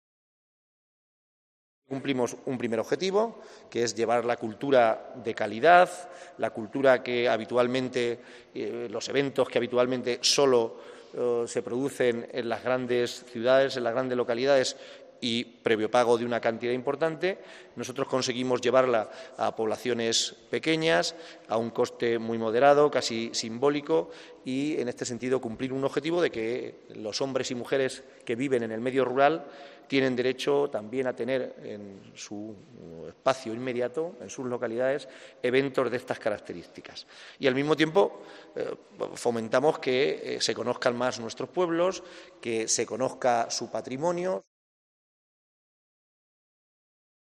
José Manuel Caballero, presidente Diputación Ciudad Real